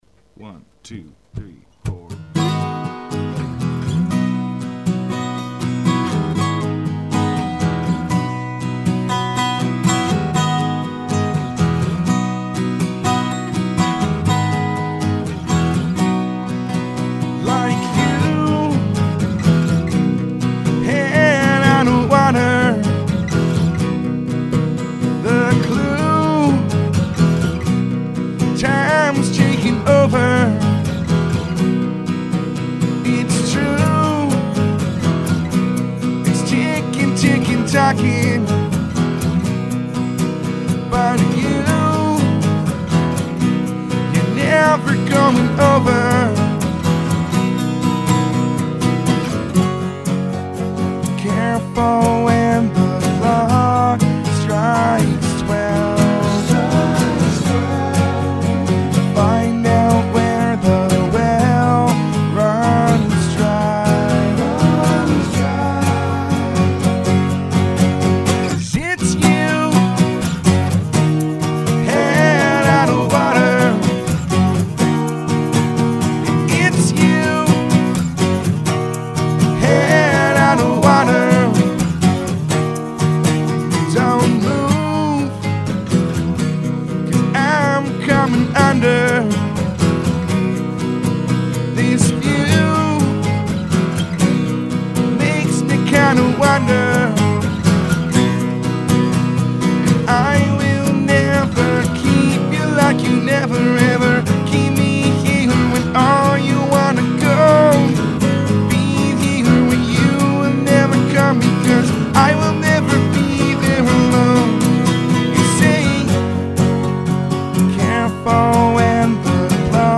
And all three groups laid down a live acoustic cut.